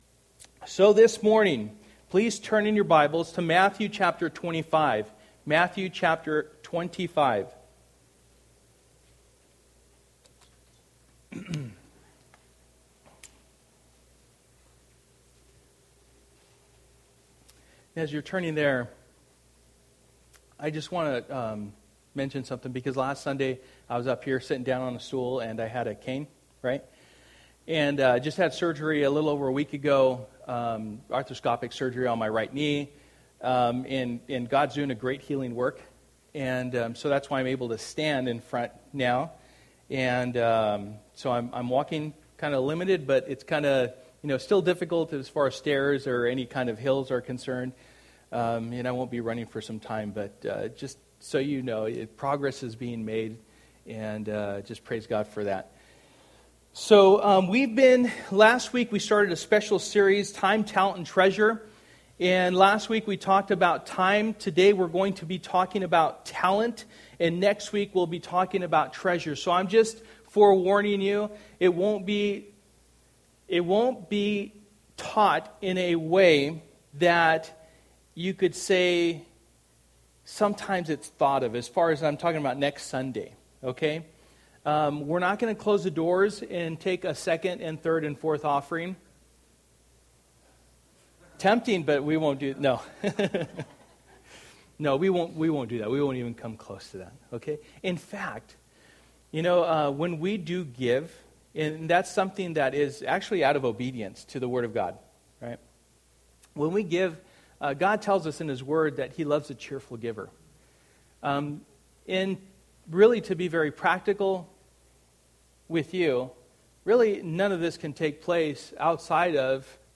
and Treasure Passage: Matthew 25:14-30 Service: Sunday Morning %todo_render% « A Heart for the People Time